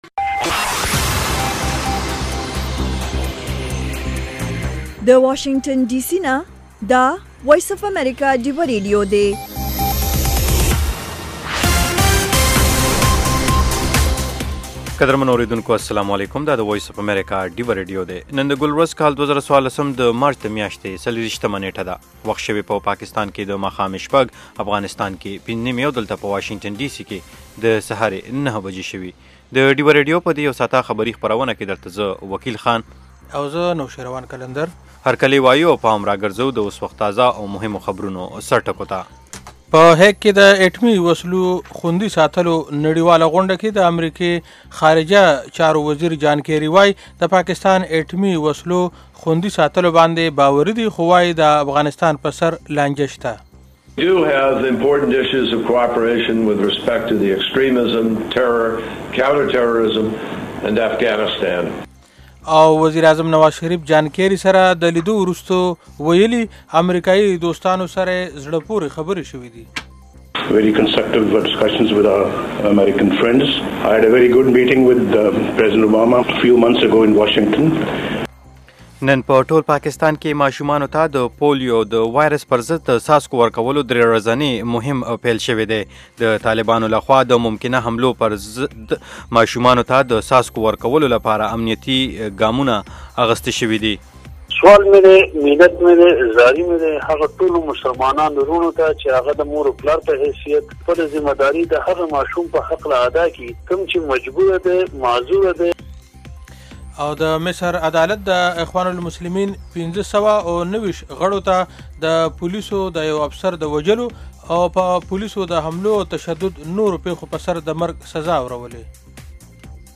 خبرونه - 1300
د وی او اې ډيوه راډيو ماښامنۍ خبرونه چالان کړئ اؤ د ورځې د مهمو تازه خبرونو سرليکونه واورئ.